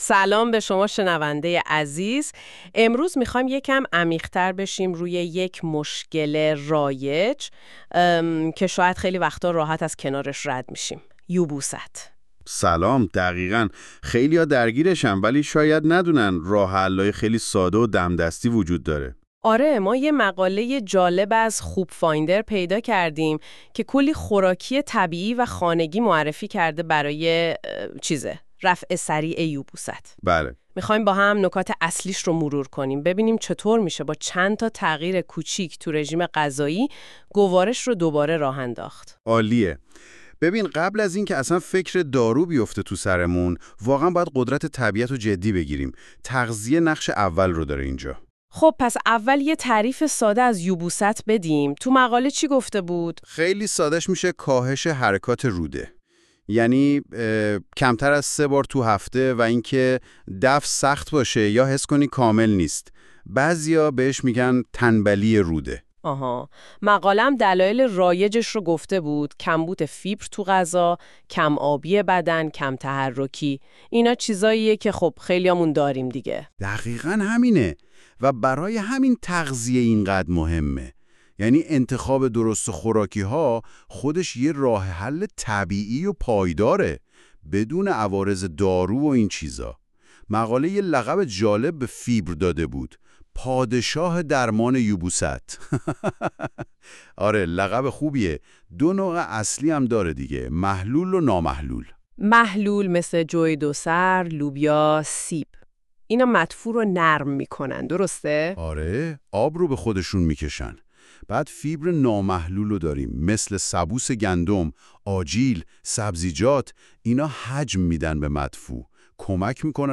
🎧 خلاصه صوتی بهترین خوراکی ها برای درمان فوری یبوست
این خلاصه صوتی به صورت پادکست و توسط هوش مصنوعی تولید شده است.